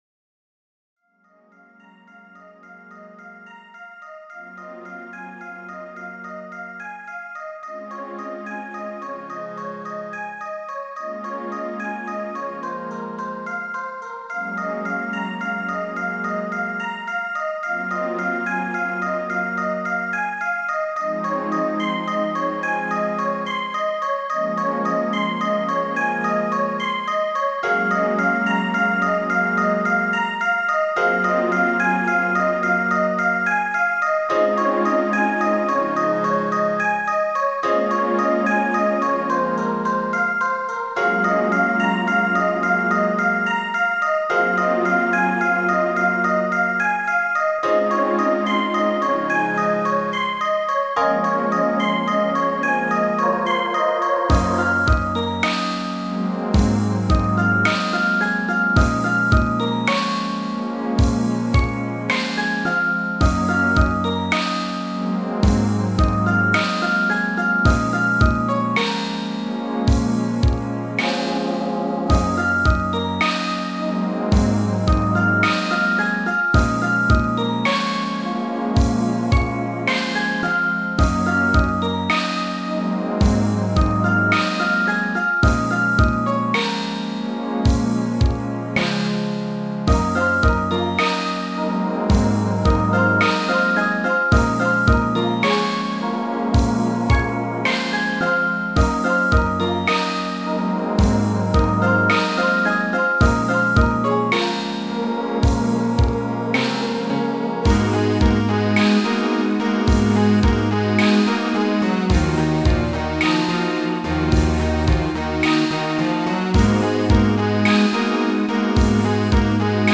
なんとか我流で「アジアっぽいクリスマス」感は出してみたつもりだが。